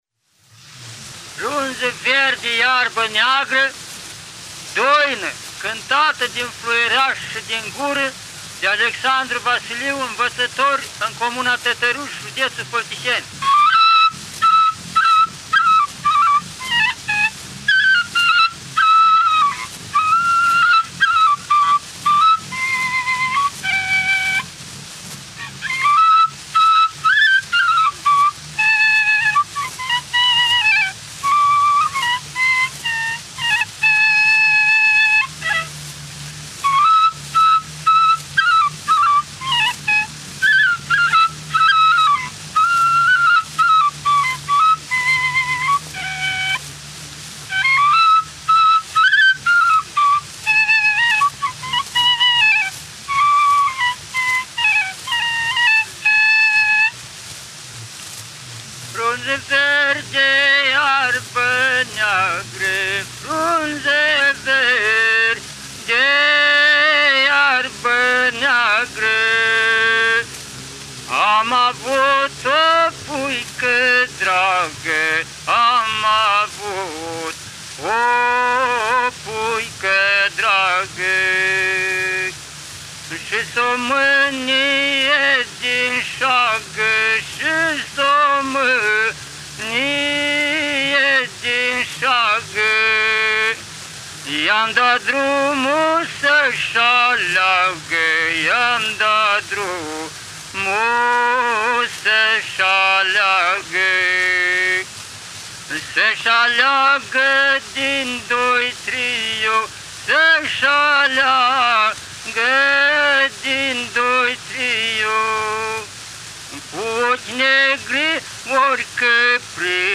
Amintim toate acestea, dragi prieteni, pentru că autoritățile locale ne-au pus la dispoziție câteva înregistrări cu renumitul dascăl, înregistrări realizate în anul 1928 de către un profesor de la Sorbona și în ediția de astăzi a emisiunii noastre vă invităm să ascultați – la fluier și cu vocea – câteva din melodiile populare din repertoriul vestitului folclorist, iar doina Foaie verde iarbă neagră o postăm, spre ascultare, aici, pe site-ul Radio Iași.